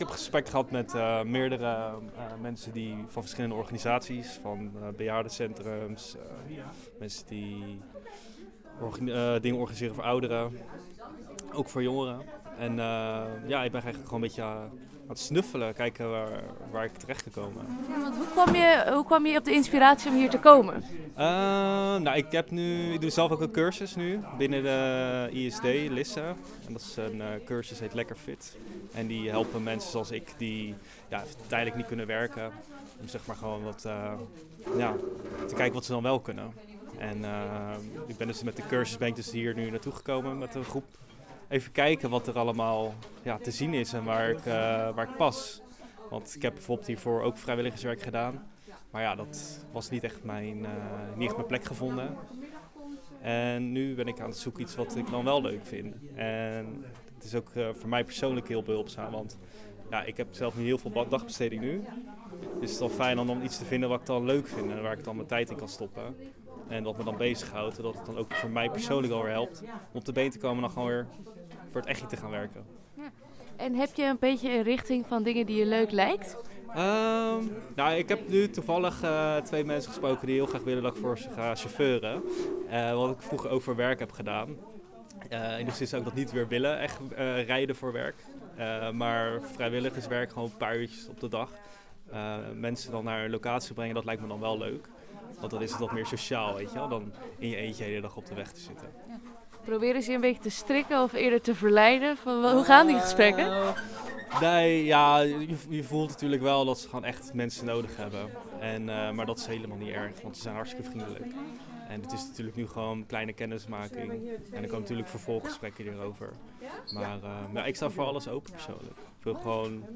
Audio reportage